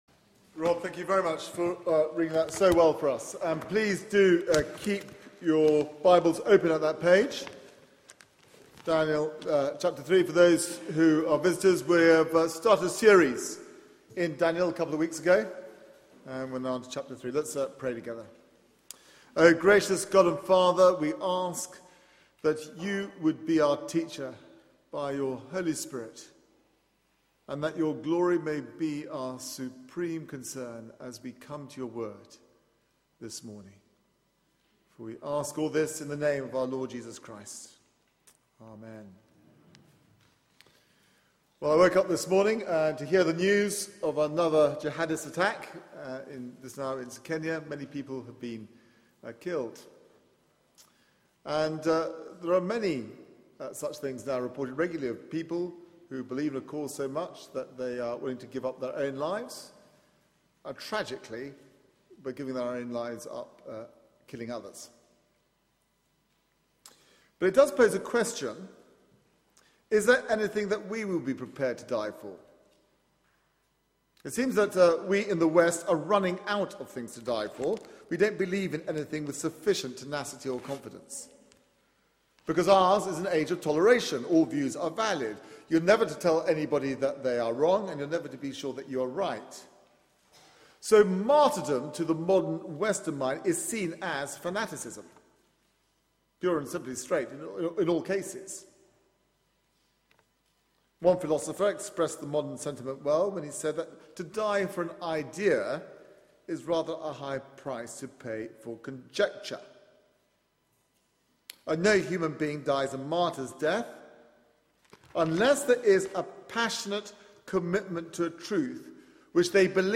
Media for 9:15am Service on Sun 22nd Sep 2013 09:15 Speaker
Faith under fire Sermon